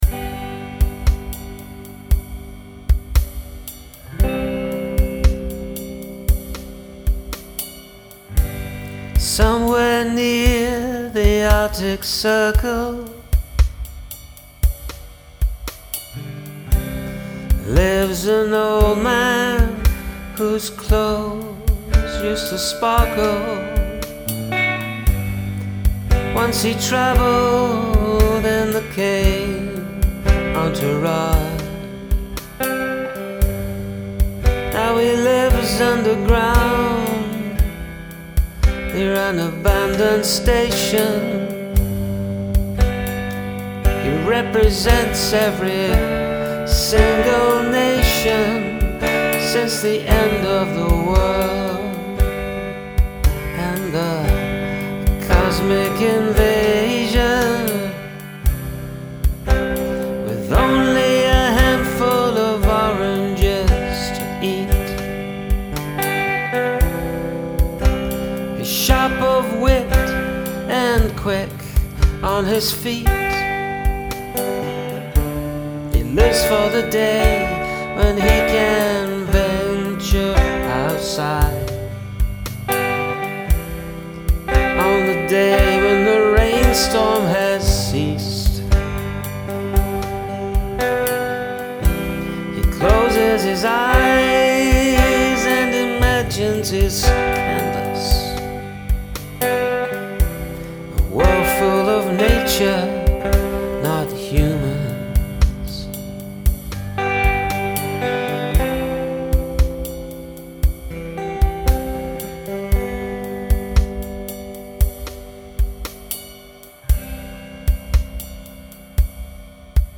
Great sound from the jump. Then THAT voice comes in -- laconic and focused, grabbing attention.
The somber delivery and feel to the song fit the situation perfectly.
Love the tremolo guitar, too.
The bass and reverb are super satisfying listening on headphones.